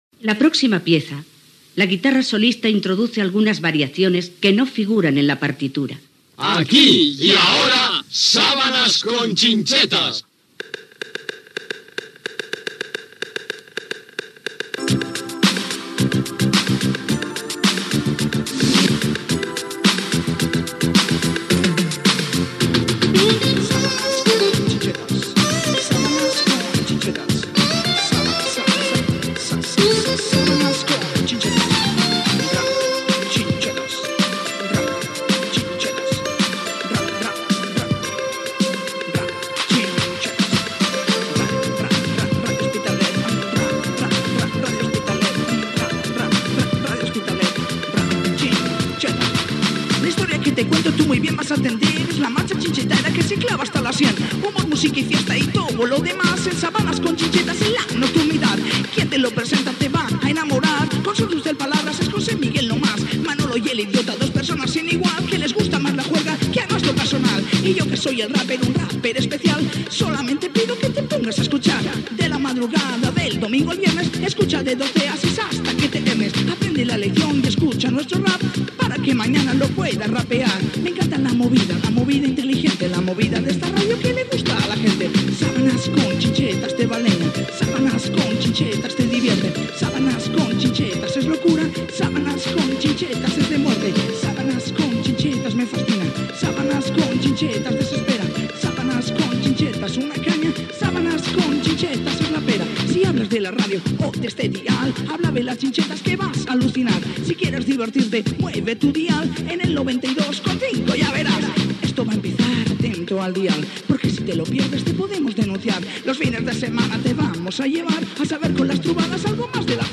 Cançó rap del programa